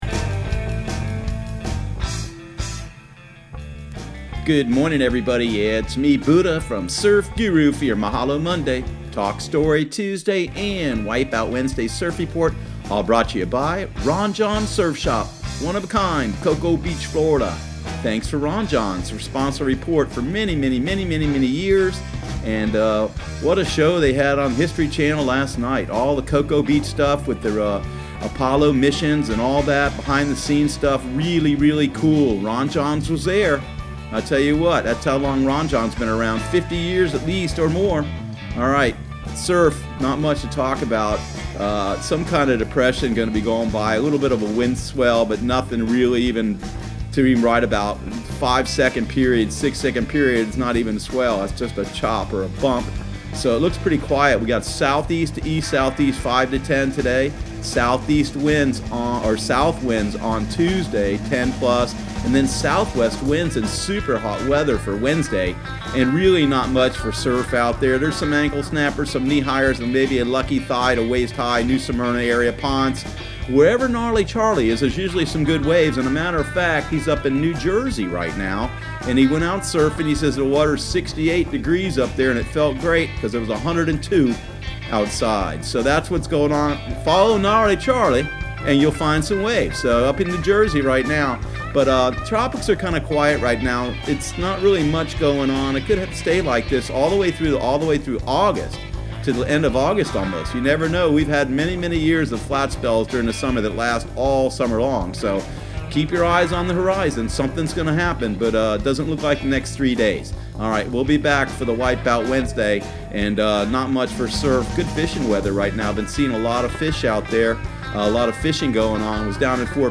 Surf Guru Surf Report and Forecast 07/22/2019 Audio surf report and surf forecast on July 22 for Central Florida and the Southeast.